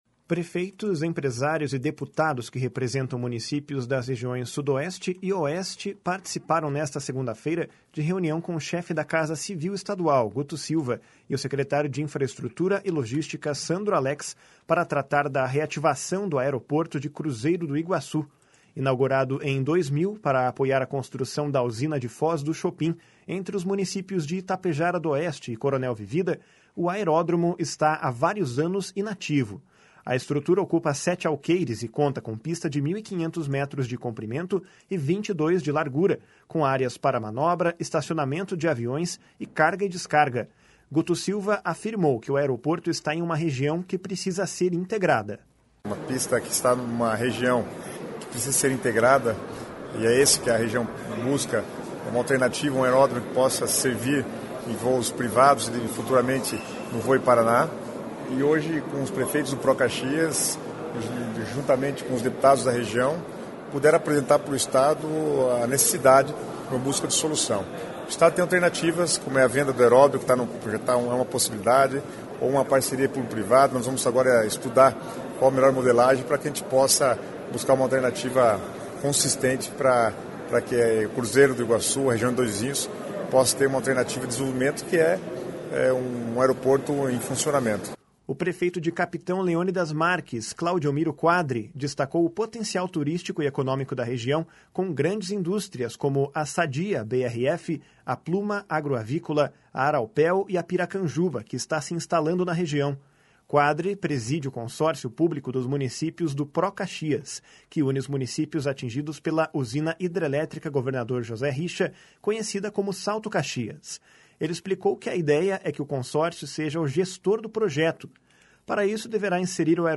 Guto Silva afirmou que o aeroporto está em uma região que precisa ser integrada. // SONORA GUTO SILVA //